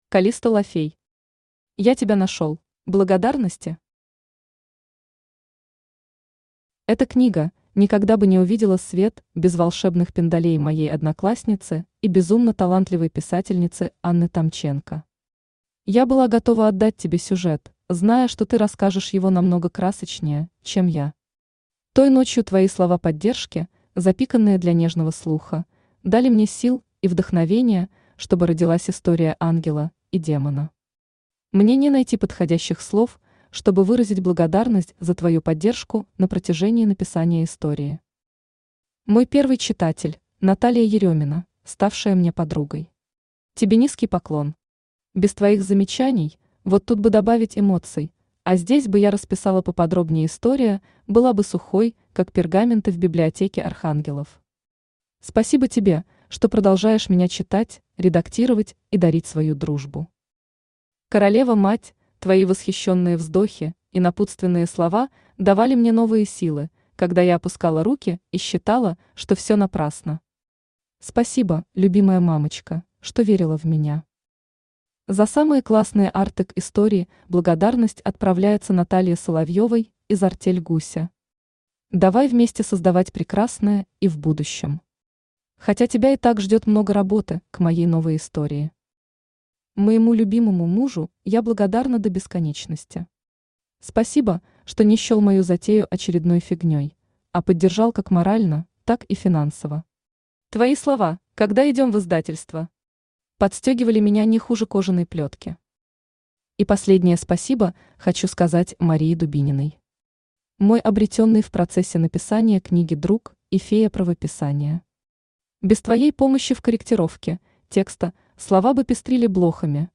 Аудиокнига Я тебя нашёл | Библиотека аудиокниг
Aудиокнига Я тебя нашёл Автор Калисто Ла Фей Читает аудиокнигу Авточтец ЛитРес.